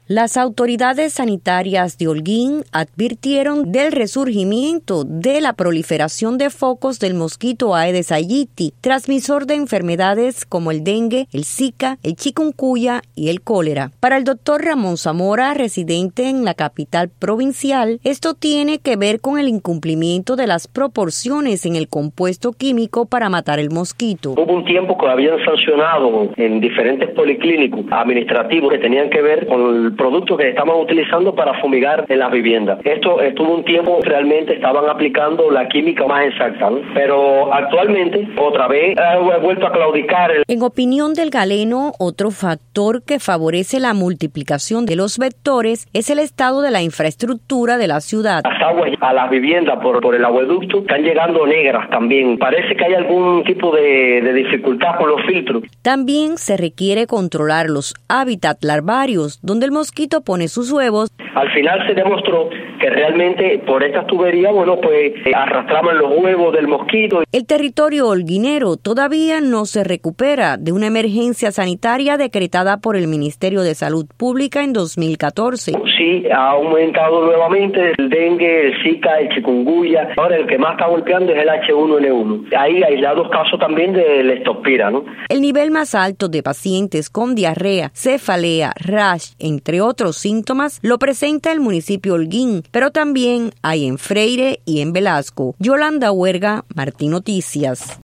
Reportaje de Radio